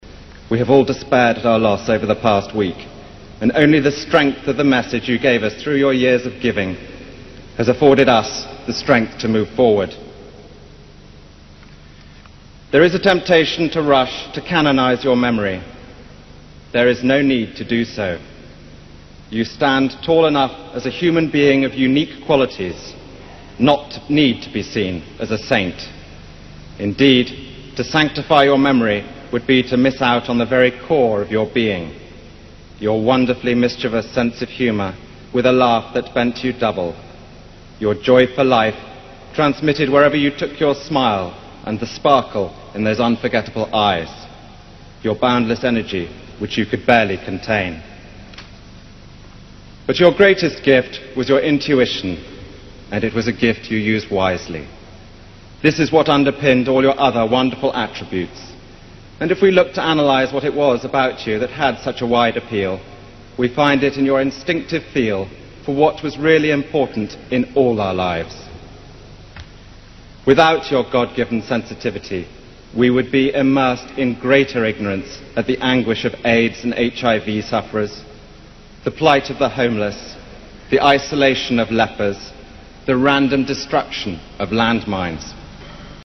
名人励志英语演讲 第118期:致戴安娜(2) 听力文件下载—在线英语听力室